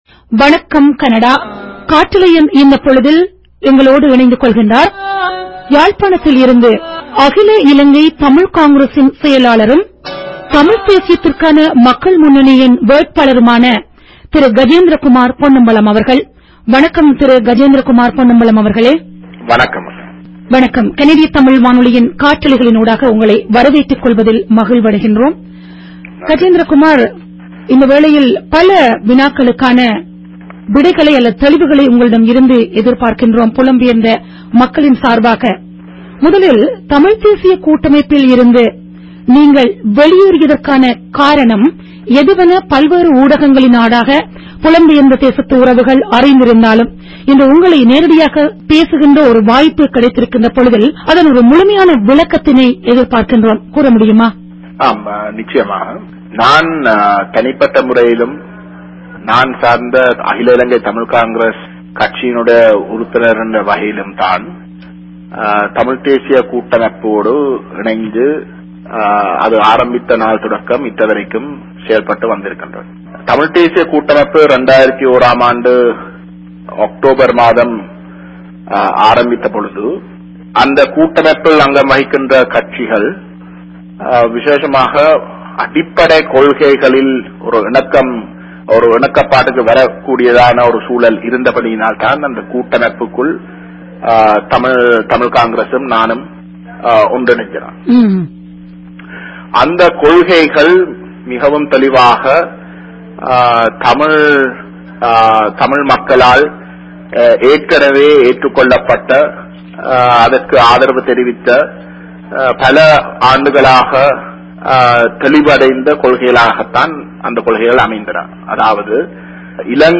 Gajendrakumar_CTR_interview.mp3